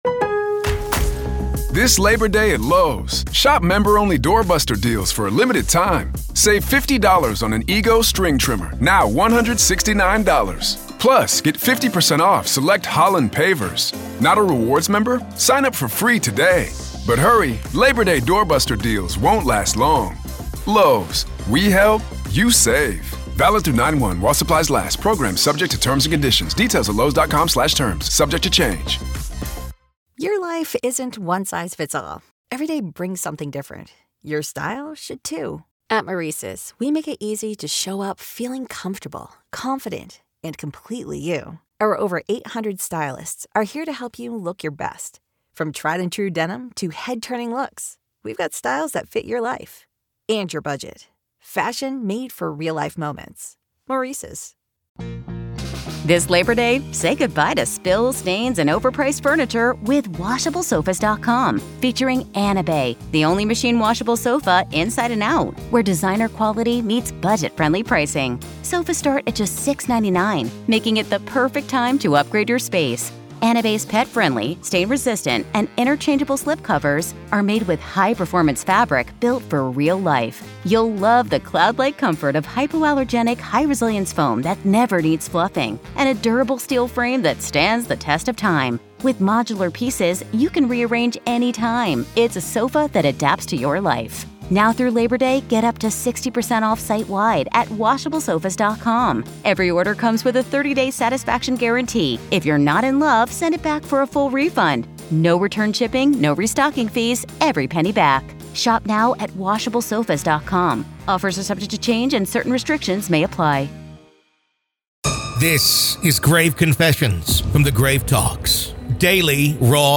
This is a daily EXTRA from The Grave Talks. Grave Confessions is an extra daily dose of true paranormal ghost stories told by the people who survived them!